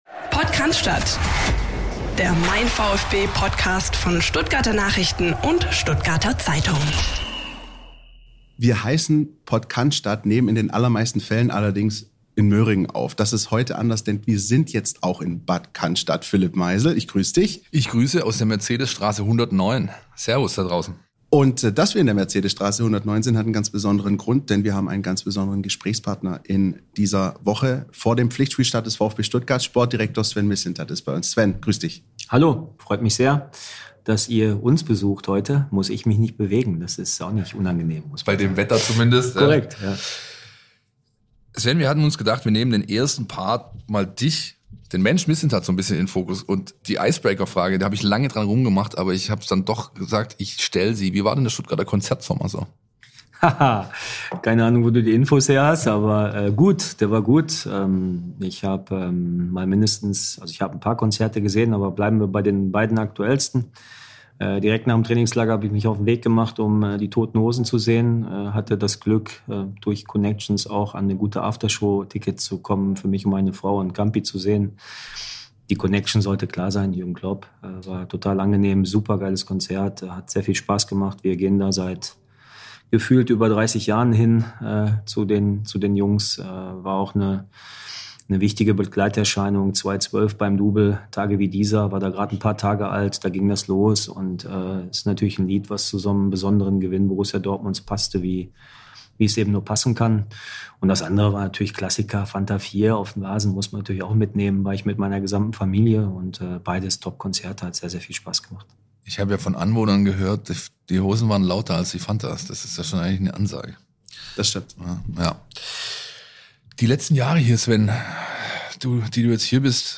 Der VfB-Sportdirektor im Gespräch [feat. Sven Mislintat] | Episode 209 ~ PodCannstatt by MeinVfB Podcast